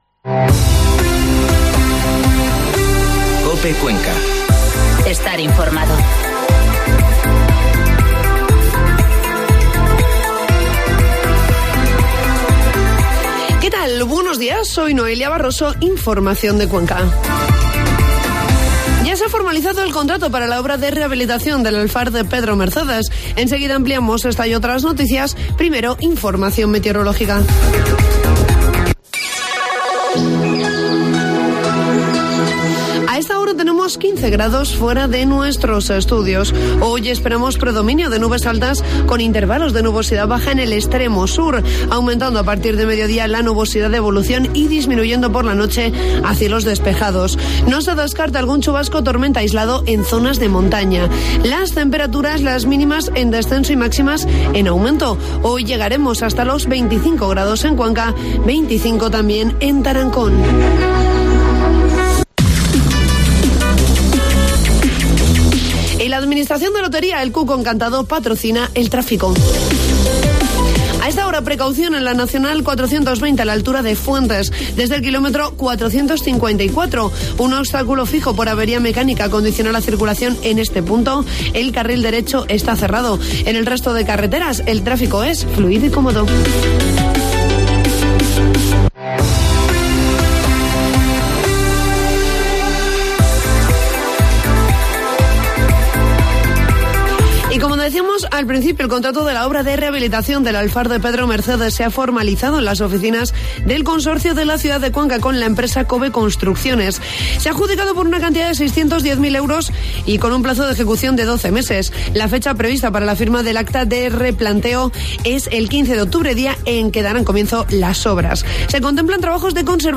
Informativo matinal COPE Cuenca 17 de septiembre